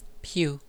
snd_pew.wav